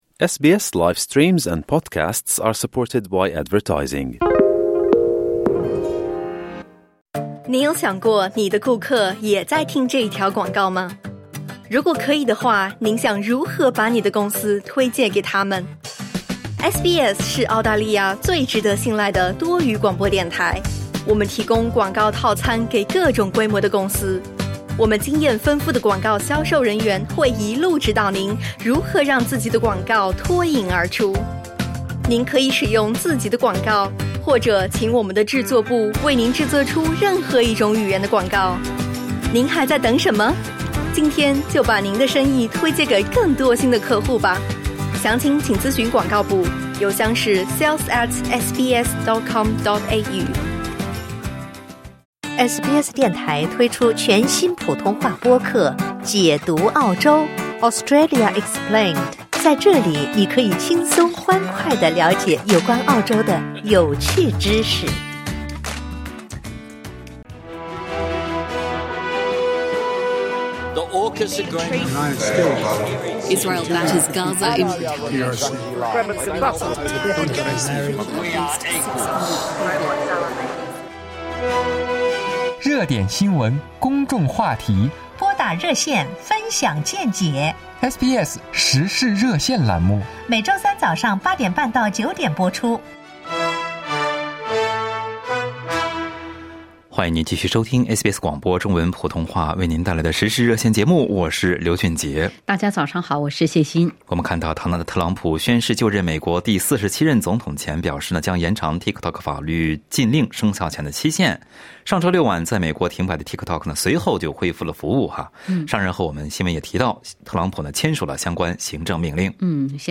在本期《时事热线》节目中，听友们就美国 TikTok 禁令的影响表达了各自的看法。